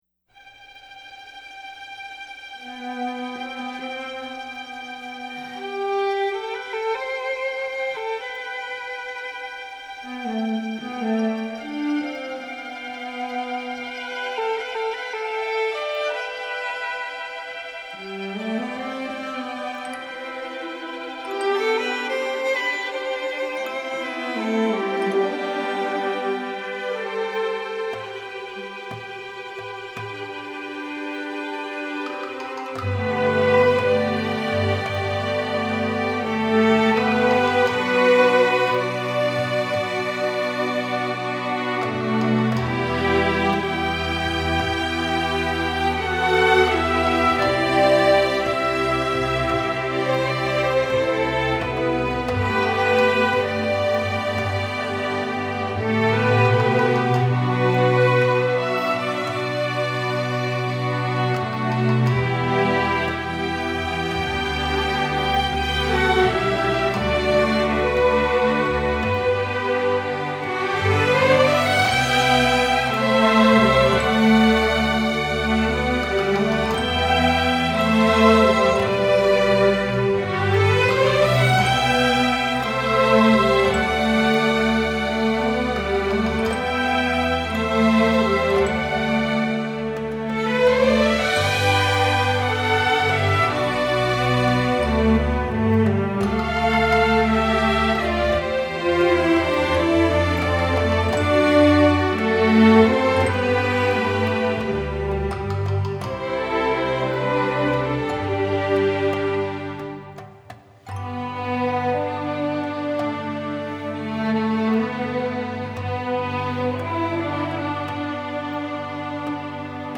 將日本豐富的音樂文化與西方管弦樂融合在一起